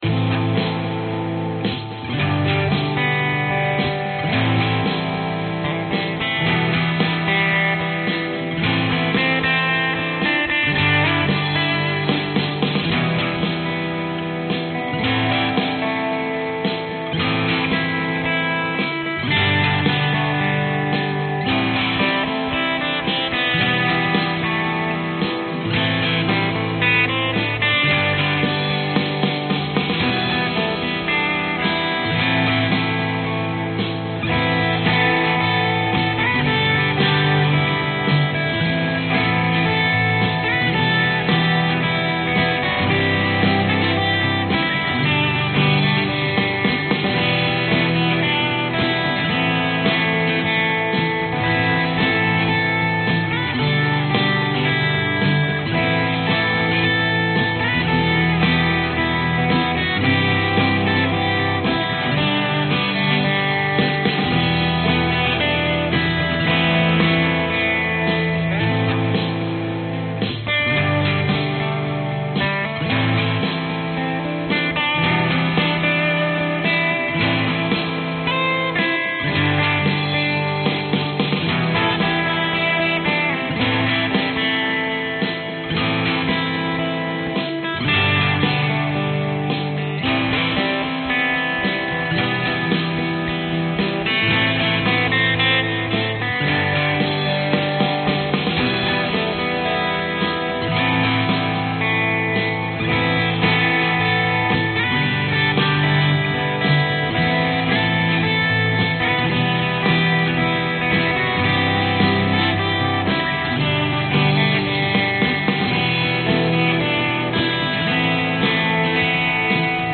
Tag: 民谣摇滚 吉他 爱尔兰民谣 摇滚 主题音乐